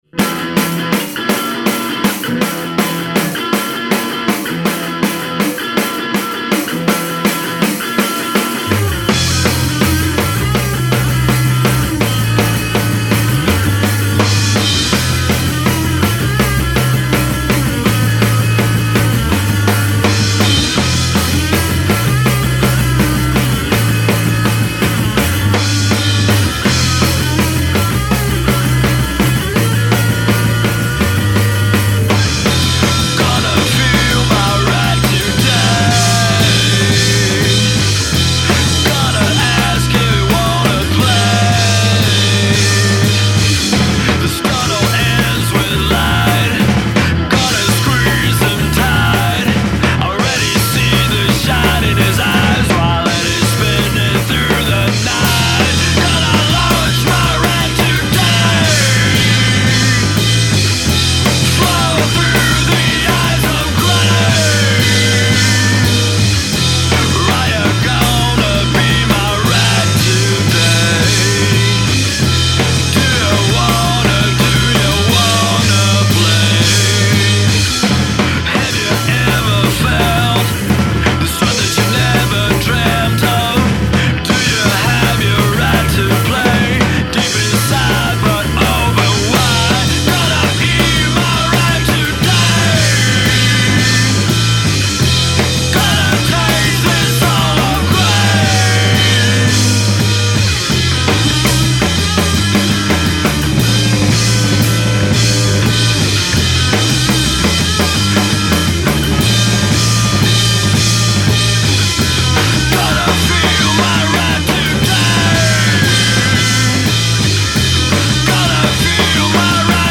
bubnjevi
gitara, vokal